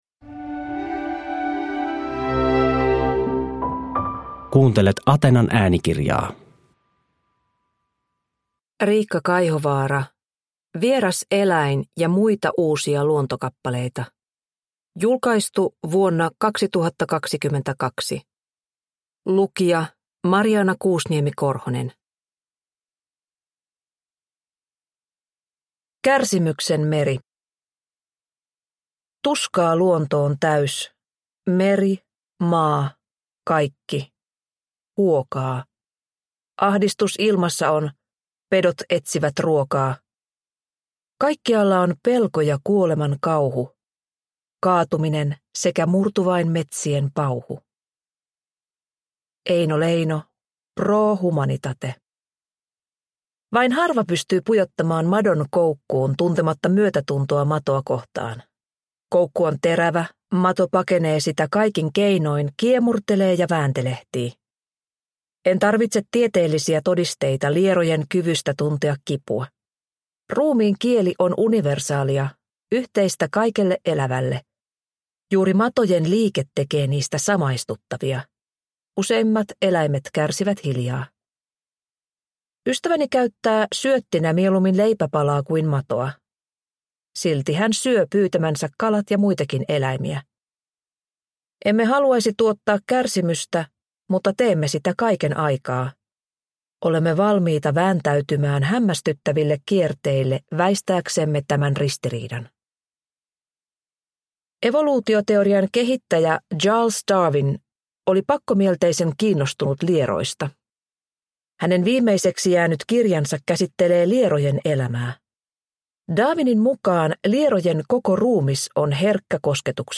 Vieras eläin – Ljudbok – Laddas ner